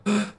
随机" sfx急促的呼吸
描述：一口气
Tag: 吸气 尖锐的呼吸 喘气 呼吸